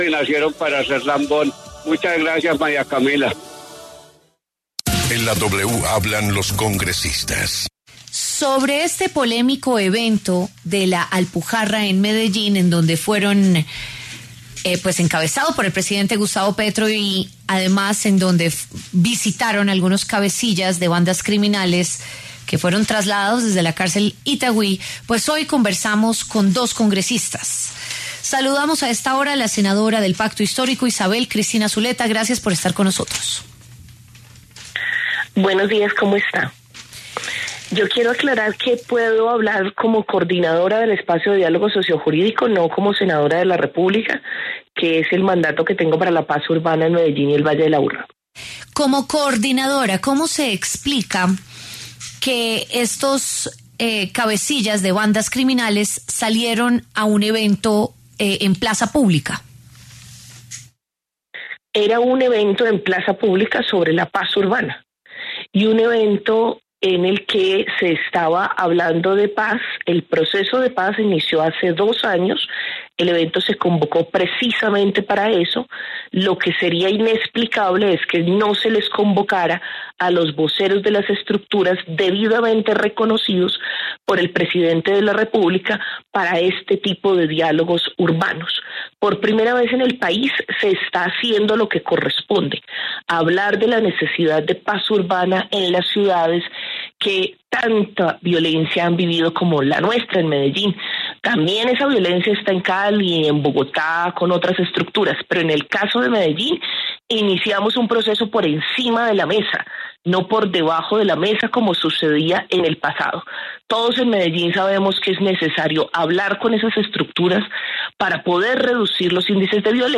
Isabel Zuleta, senadora del Pacto Histórico, y Hernán Cadavid, representante del Centro Democrático, debatieron sobre el tema en La W.